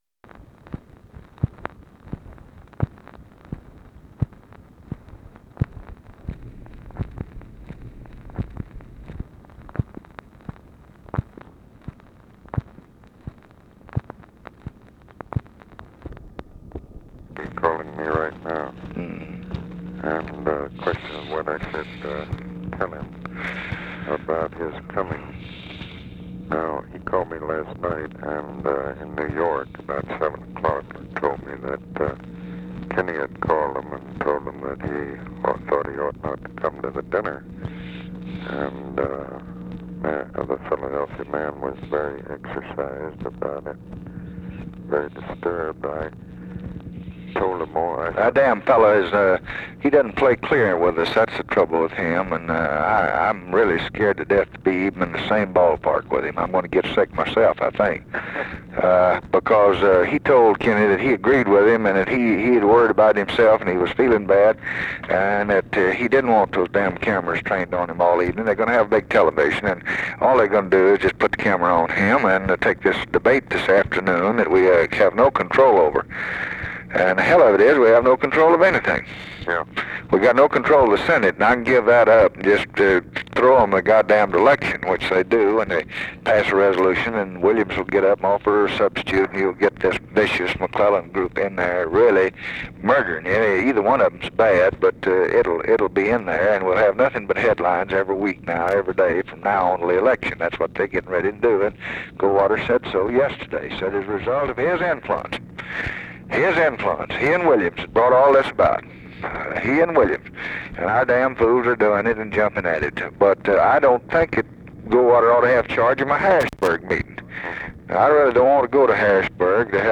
Conversation with ABE FORTAS and OFFICE CONVERSATION, September 10, 1964
Secret White House Tapes